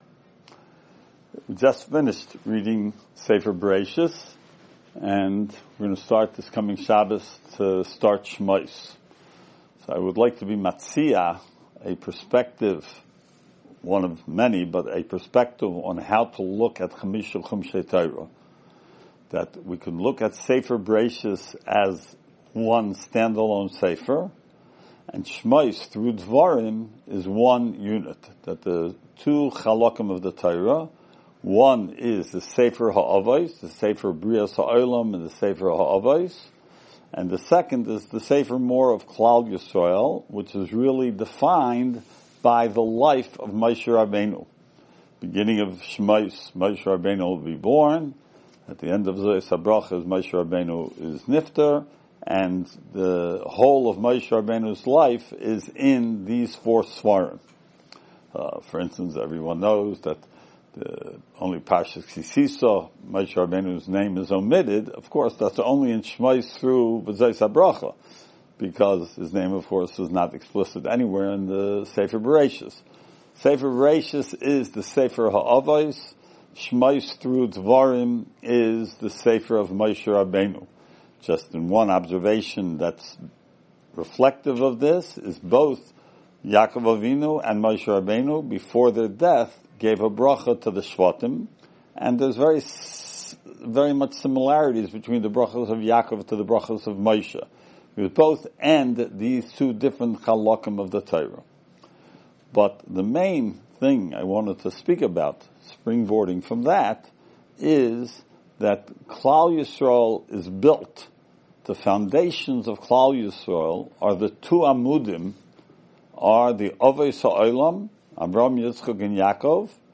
Weekly Alumni Shiur Shemos 5785